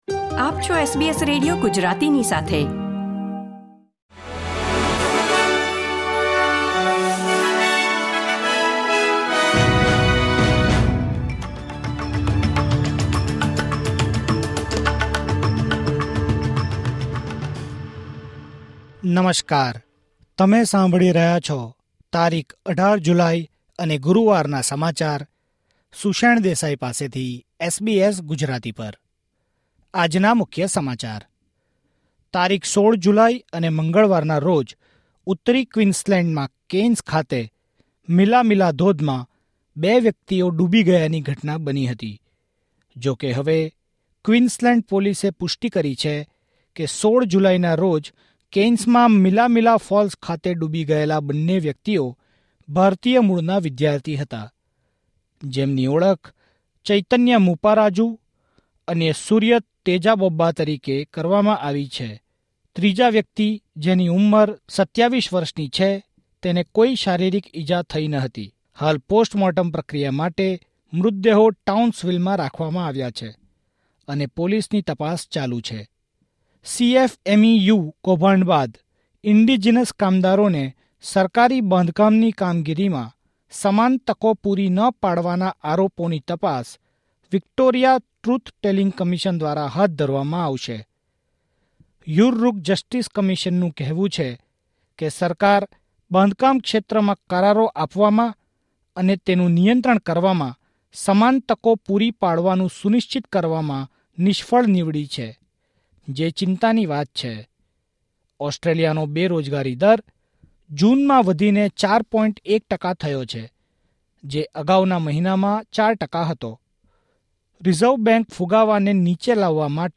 SBS Gujarati News Bulletin 18 July 2024